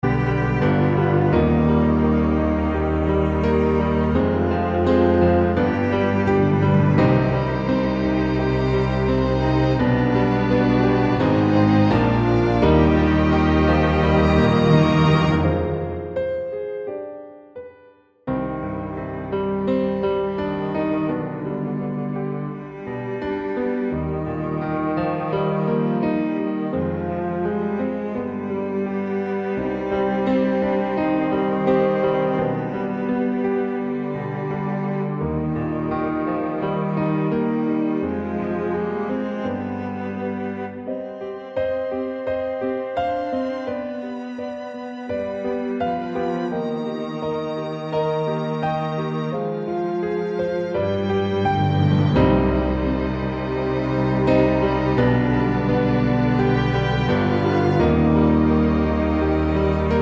Down 4 Semitones For Male